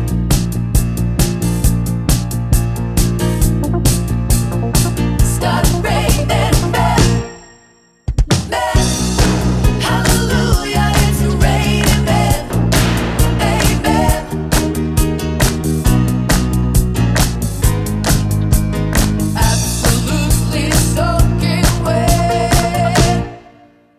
Two Semitones Down Pop (1980s) 3:42 Buy £1.50